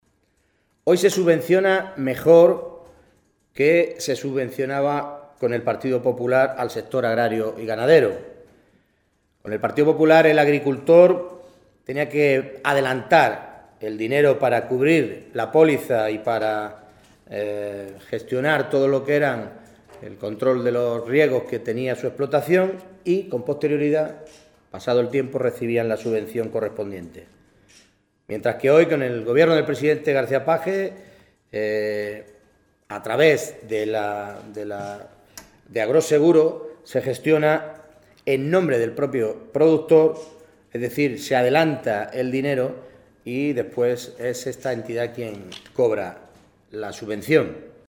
Sáez, que realizó estas declaraciones momentos antes del inicio de la comisión de Agricultura de las Cortes regionales donde se abordaba este asunto, indicó que esta cifra viene a poner de manifiesto la importancia que da el actual gobierno a que los agricultores y ganaderos puedan contar con una cobertura de sus cosechas y explotaciones.
Cortes de audio de la rueda de prensa